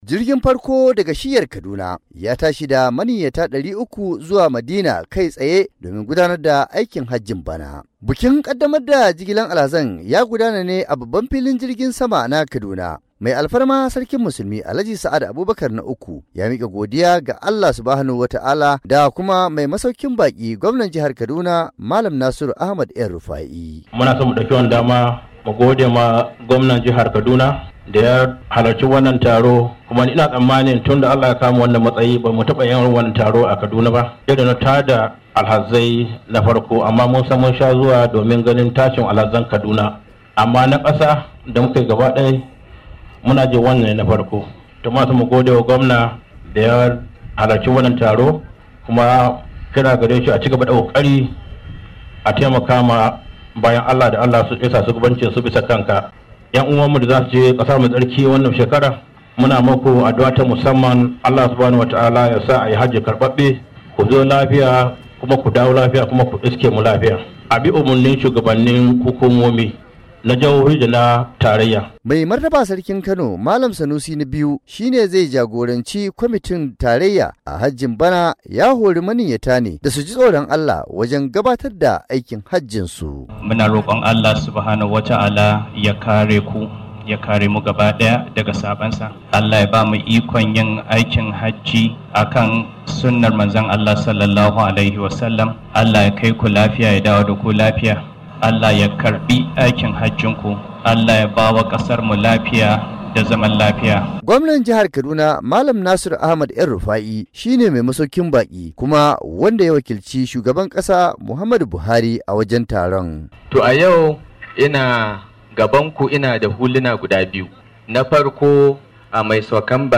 Rahoton aikin hajjin bana-3:06"